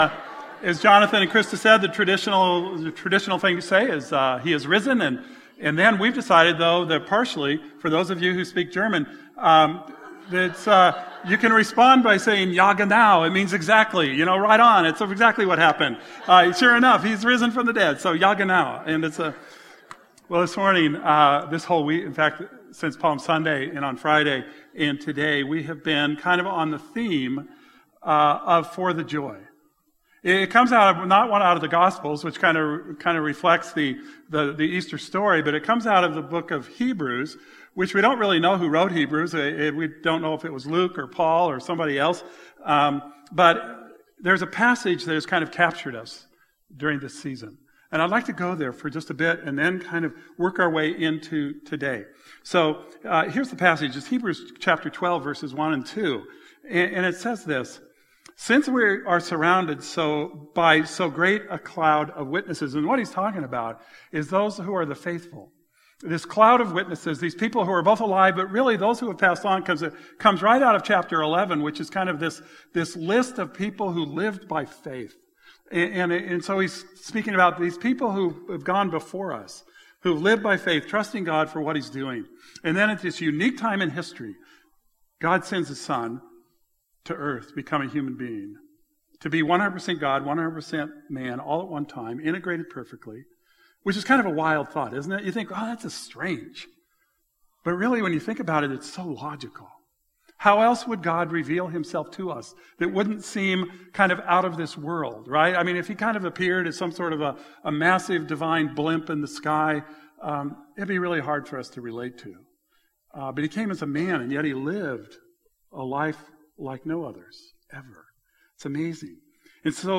Easter service focuses on the importance of Jesus in our lives... how He effected lives of those in the Bible and how He effects lives of those here and now.